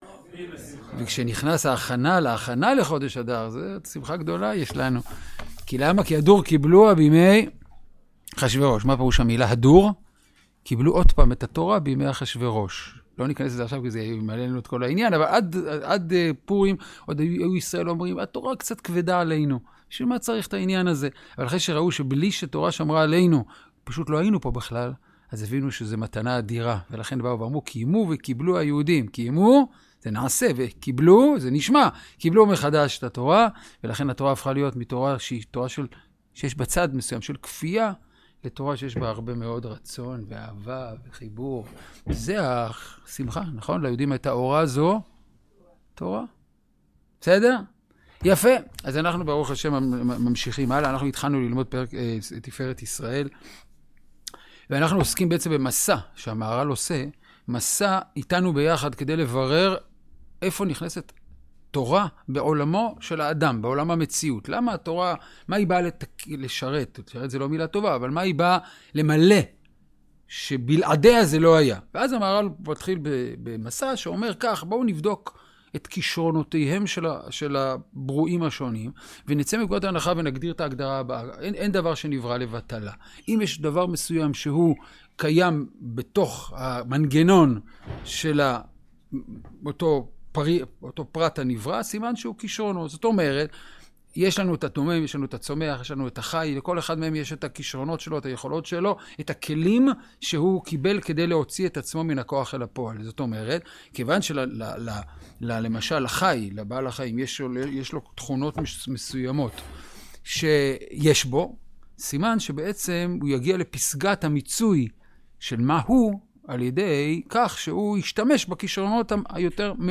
סדרת שיעורי